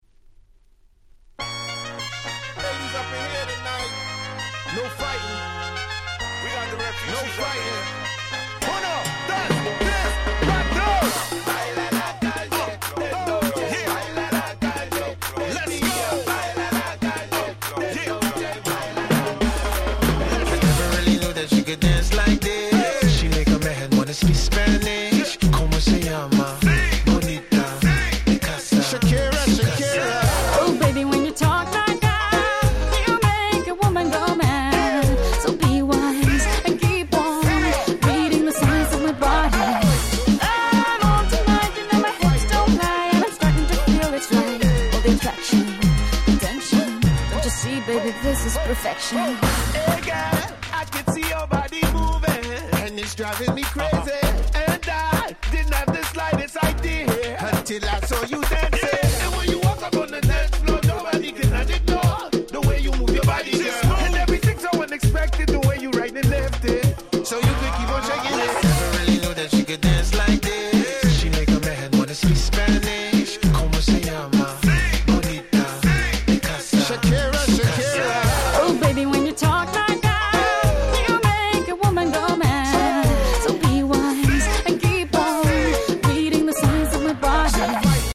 05' Super Hit Reggaeton/R&B !!
イントロドン！など頭から盛り上がり必至の問答無用のParty Tune !!